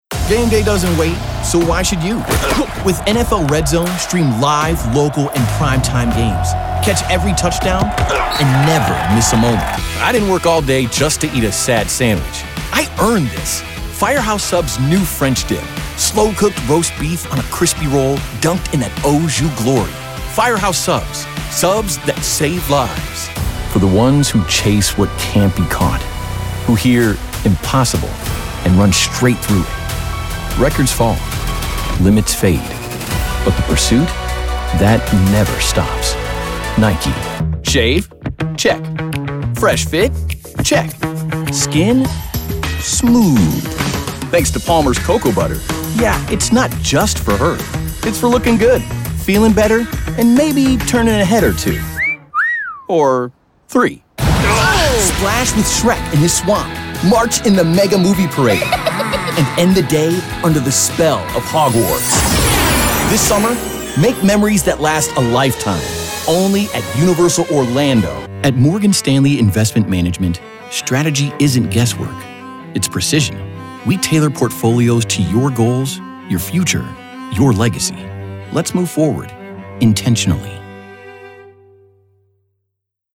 Commercial Demo
'Male'
Source-Connect, Video Call, Private Studio
Mac M1 (2021), Sennheiser MKH 416, Roswell K87, Logic Pro
English (neutral)
Young Adult, Adult, Middle-Aged
Deep/Low/Baritone, Conversational, Friendly/Calm/Soothing, Enthusiastic, Informative, Announcer